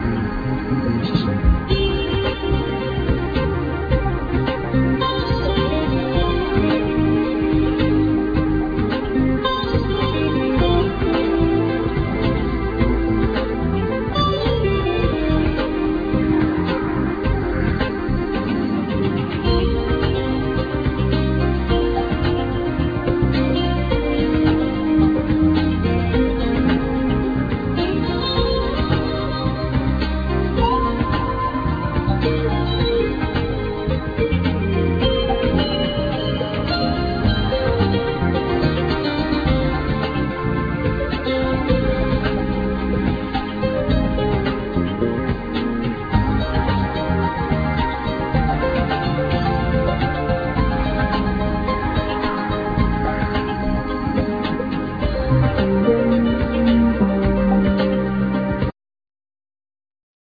Piano,Keyboards,Programming
Guitar
Drums,Percussions
Flute